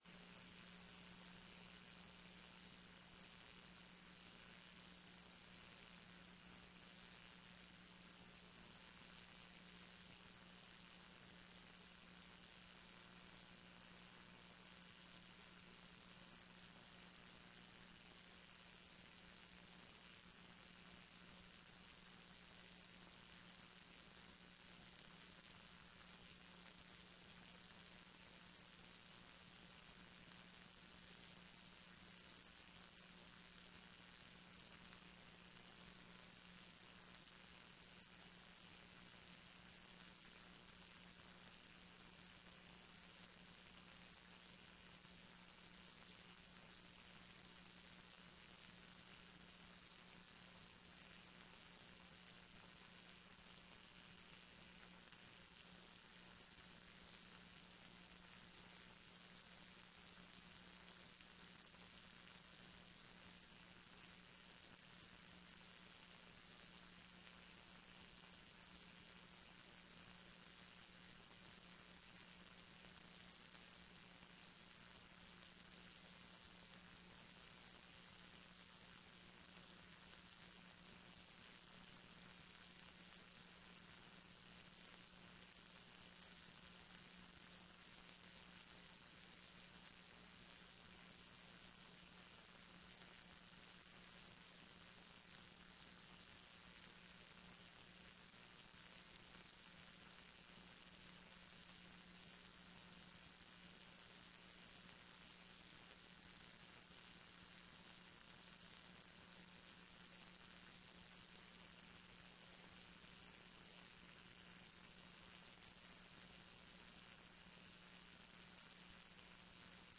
TRE-ES sessao do dia 10 de setembro de 2014